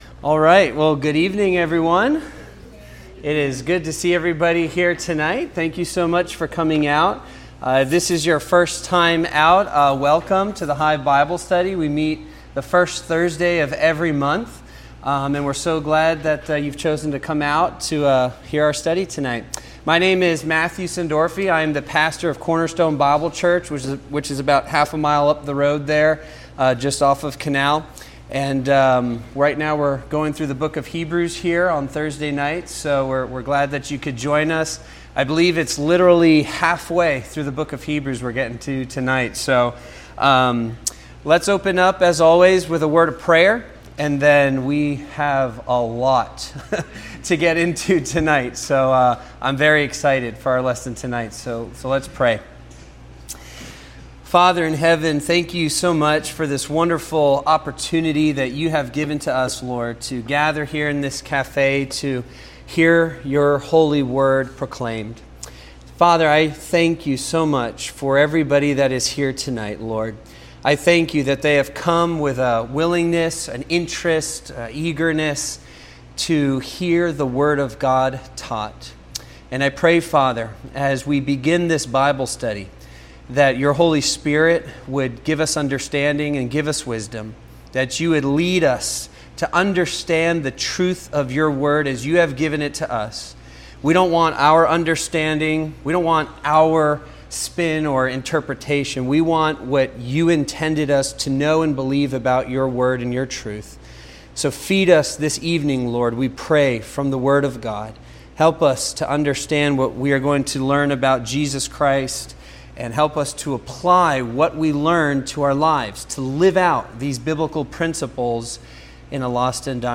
The Hive Bible Study - Hebrews 7